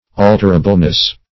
Search Result for " alterableness" : The Collaborative International Dictionary of English v.0.48: Alterableness \Al"ter*a*ble*ness\, n. The quality of being alterable; variableness; alterability.